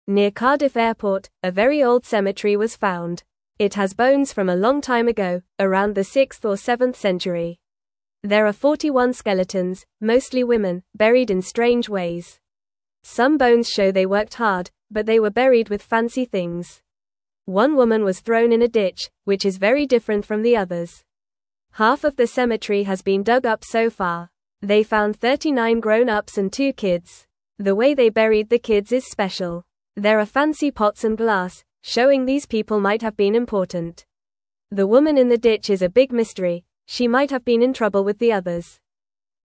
Fast
English-Newsroom-Beginner-FAST-Reading-Old-Cemetery-Found-Near-Cardiff-Airport.mp3